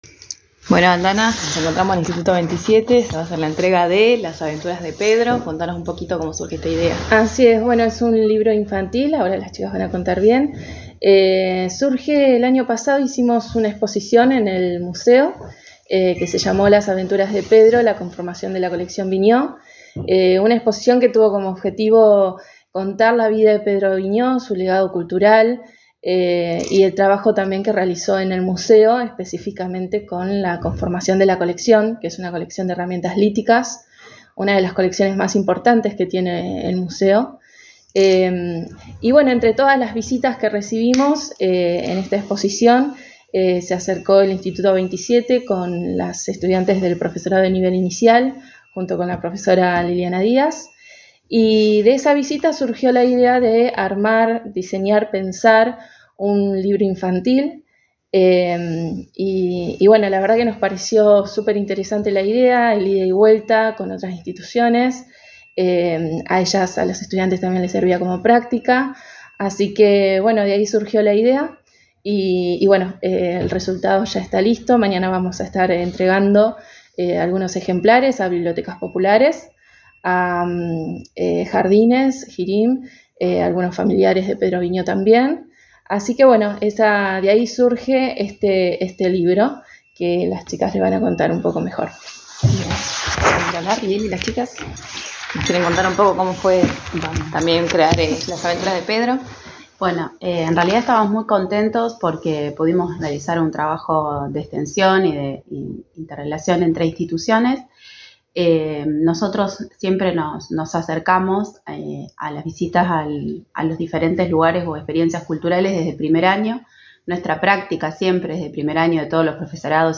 Conferencia de Prensa del Instituto 27